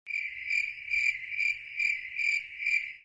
Crickets.mp3